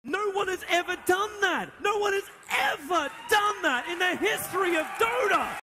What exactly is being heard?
Tags: Commentary